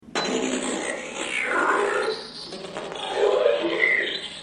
Android_Activation_Yes.mp3